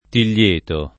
[ til’l’ % to ]